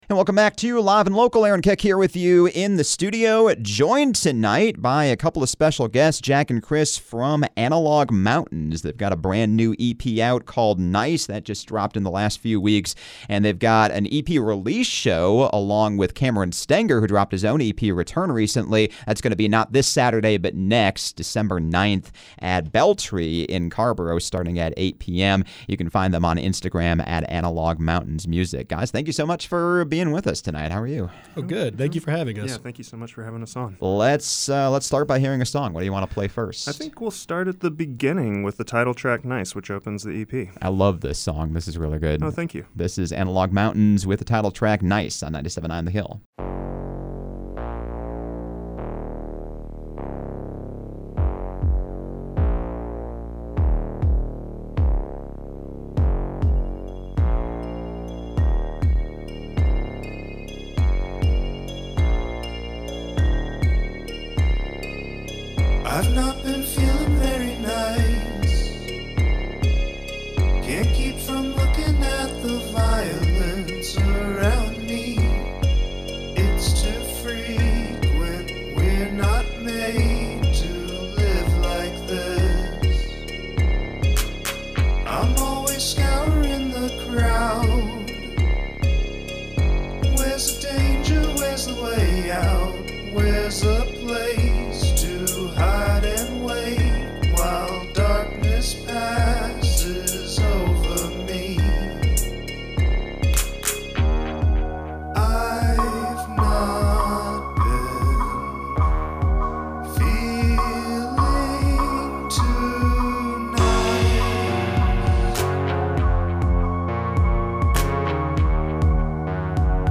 songs that sound bright and upbeat musically